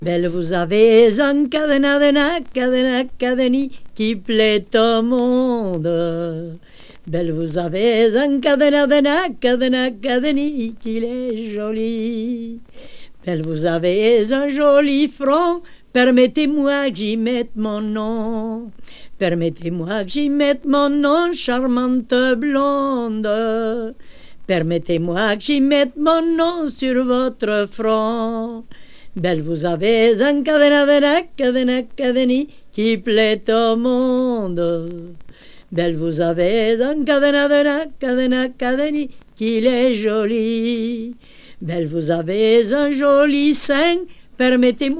本盤ではフランスを題材に、南仏の伝承歌を収録。
Folk, World　France　12inchレコード　33rpm　Stereo